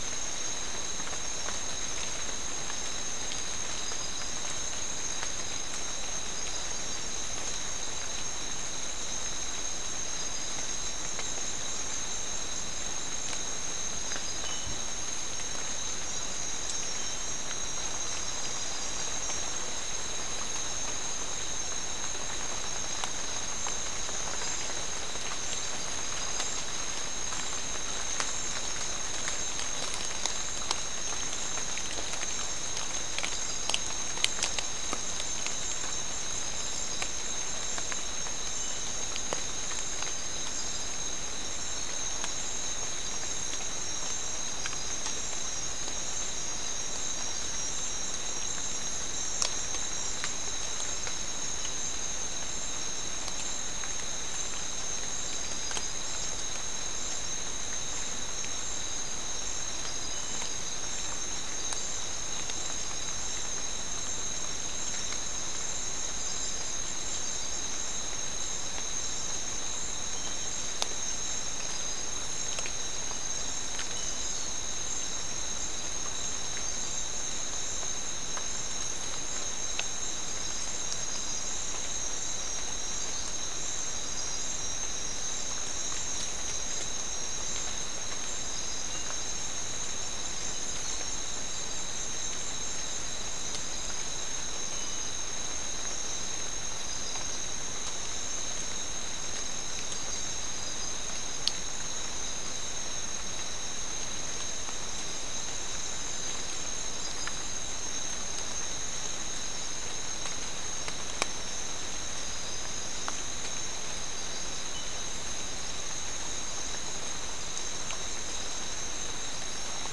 Soundscape Recording
South America: Guyana: Turtle Mountain: 2
Recorder: SM3